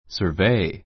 səː r véi サ～ ヴェ イ
sə́ː r vei サ ～ヴェイ